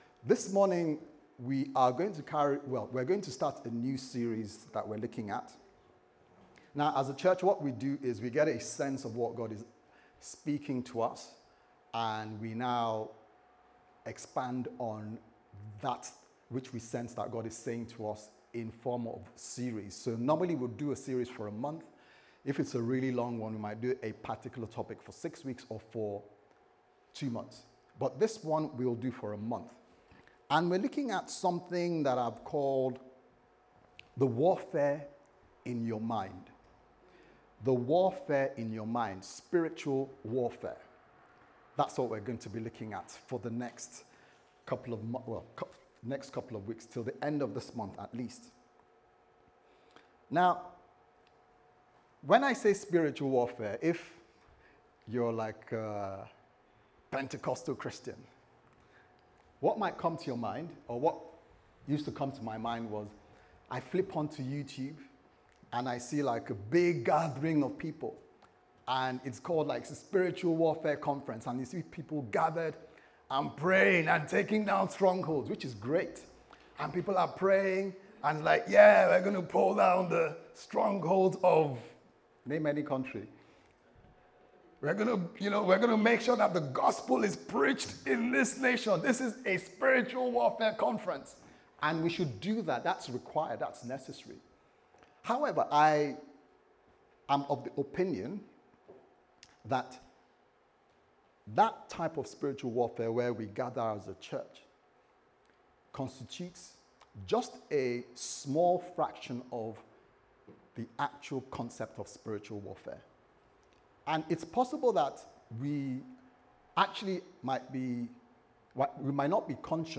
The Warfare In Your Mind Service Type: Sunday Service Sermon « What Are You Thinking About